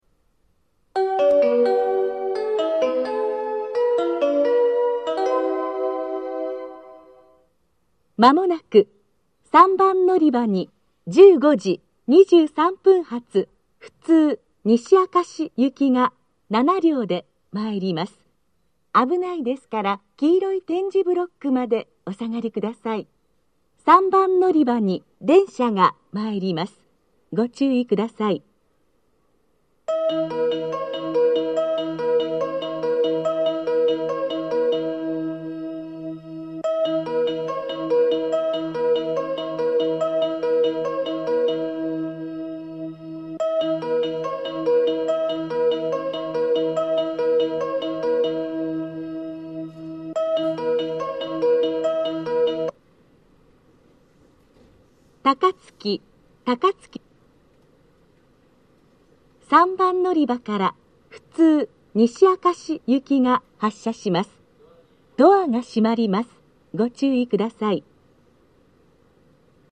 （女性）
到着放送は途中で切られています。
接近放送・発車放送